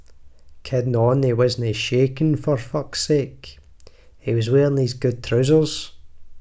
Text-to-Speech
glaswegian
scottish